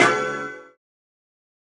OZ-Sound (Hit).wav